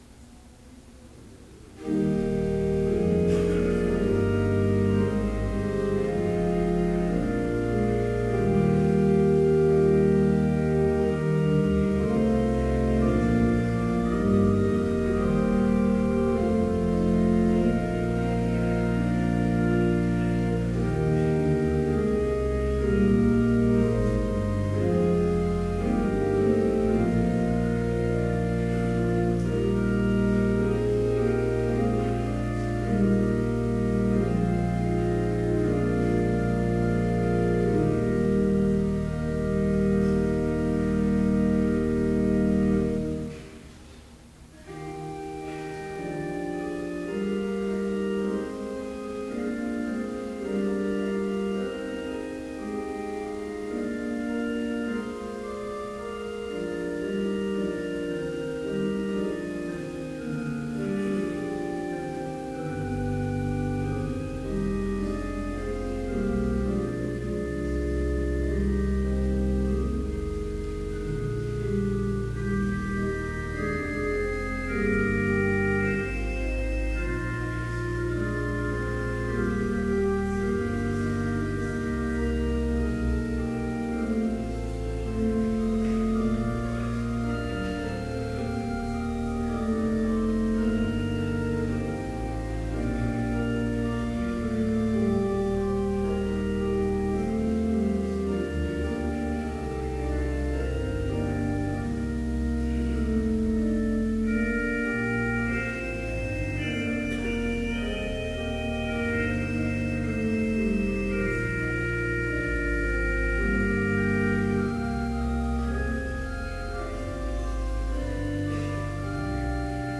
Chapel worship service in BLC's Trinity Chapel
Complete service audio for Summer Chapel - June 11, 2014